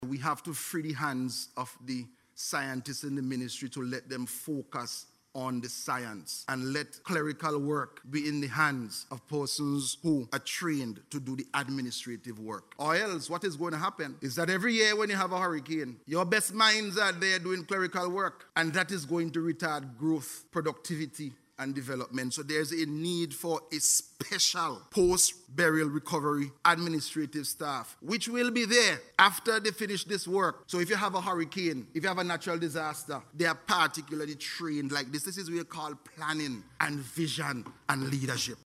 While making his contribution to the recent budget debate, Minister of Agriculture Saboto Caesar, commended the government’s vision for making provisions for the recovery team, which will begin work in the month of February.